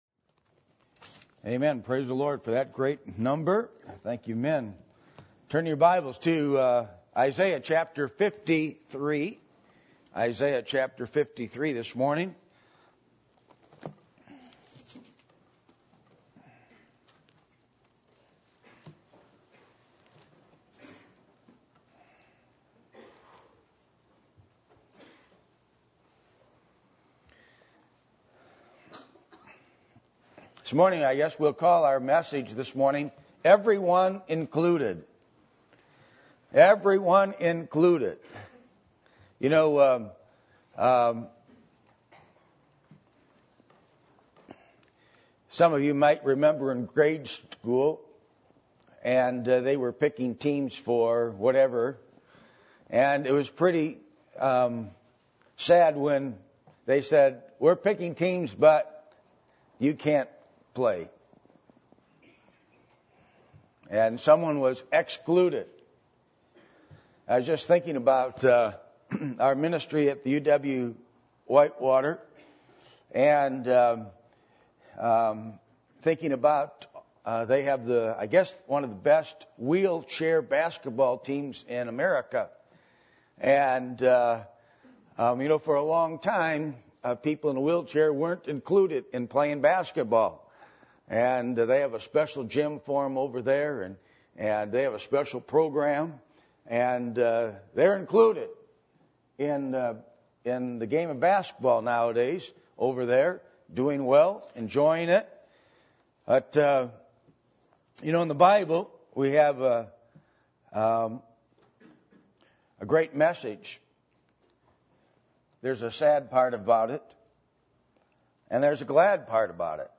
Passage: Isaiah 53:1-12 Service Type: Sunday Morning %todo_render% « How To Interpret The Bible